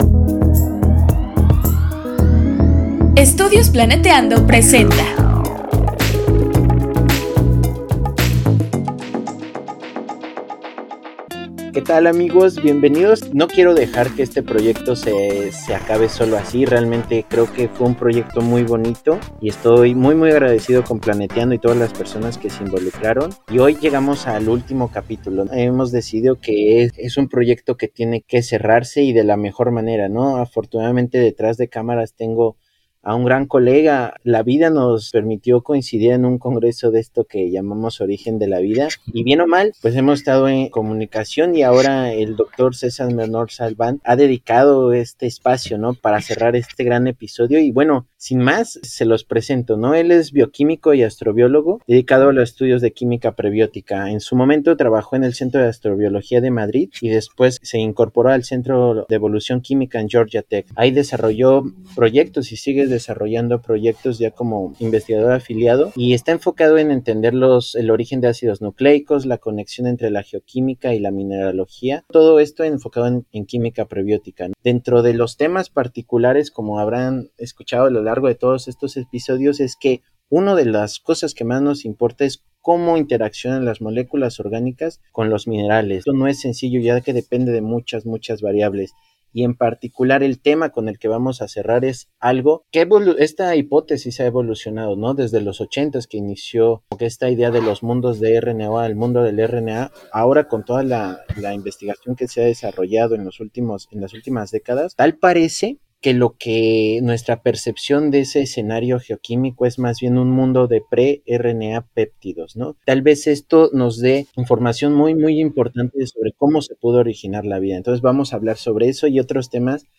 Todo parece indicar que la interacción entre ácidos nucleicos y péptidos dio paso a la formación de estructuras complejas que pudieron favorecer diversas reacciones químicas. Entrevista